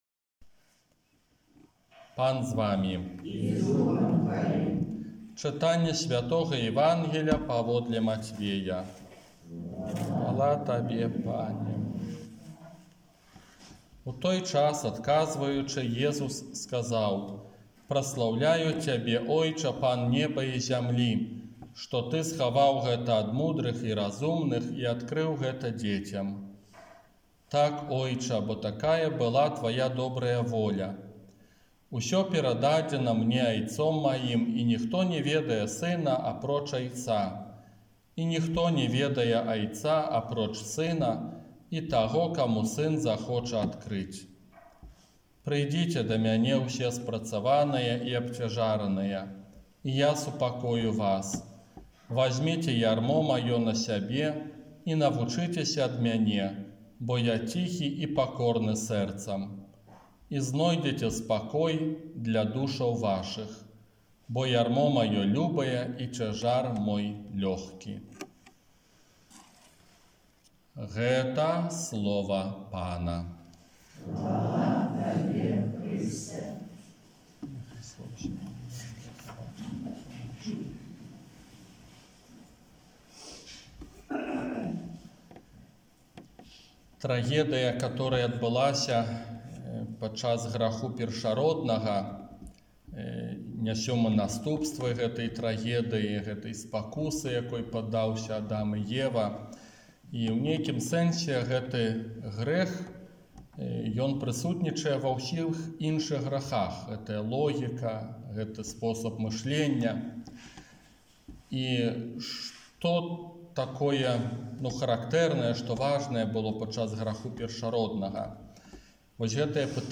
ОРША - ПАРАФІЯ СВЯТОГА ЯЗЭПА
Казанне на чатырнаццатую звычайную нядзелю